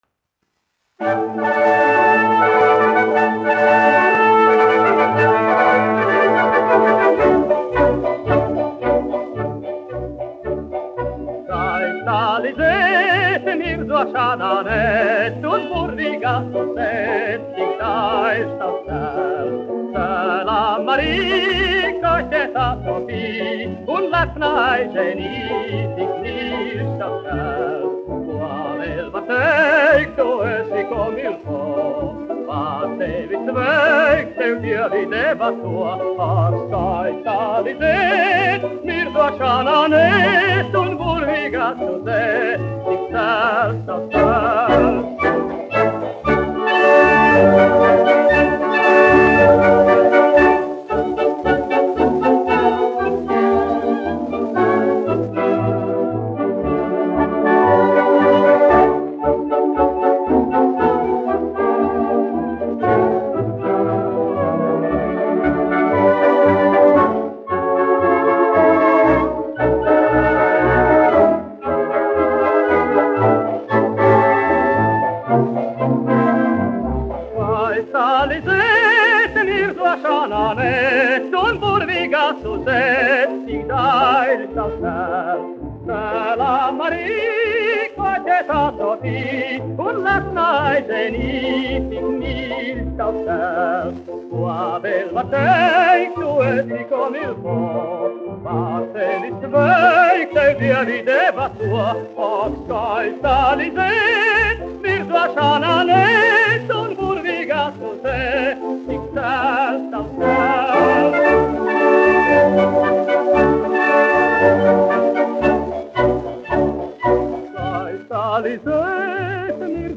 1 skpl. : analogs, 78 apgr/min, mono ; 25 cm
Kinomūzika--Fragmenti
Fokstroti
Skaņuplate